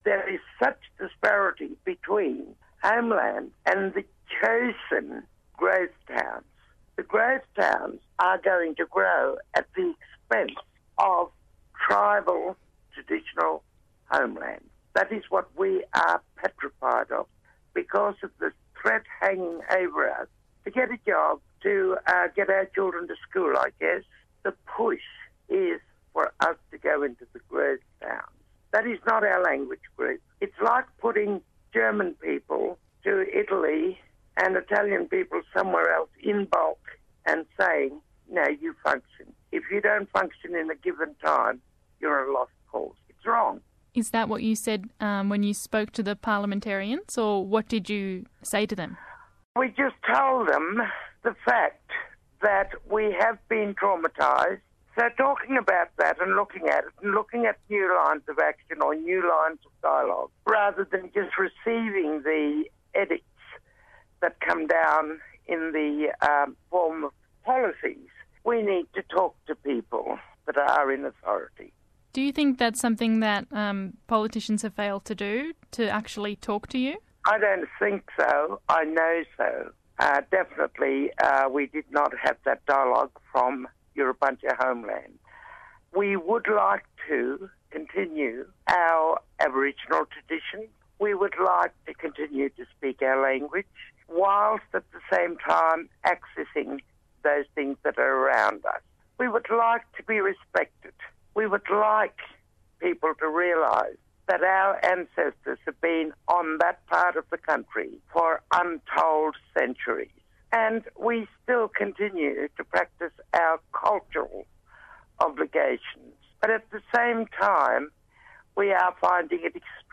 - Source: Radio Australia, 24 August 2012